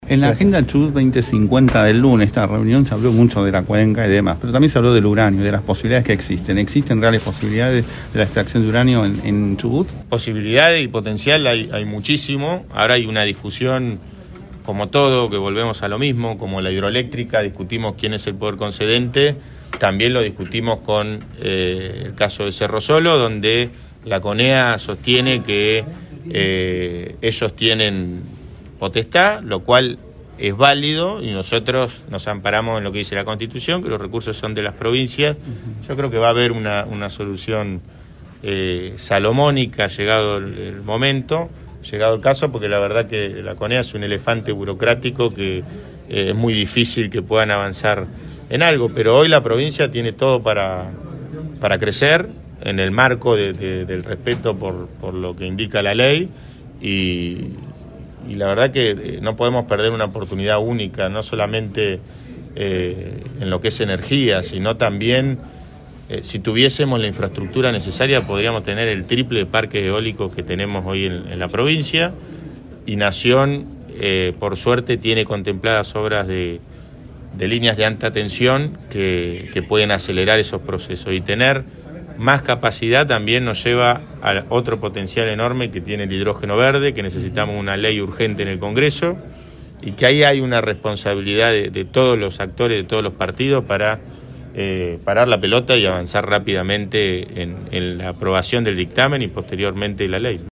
En la conferencia de prensa de hoy el gobernador habló de todo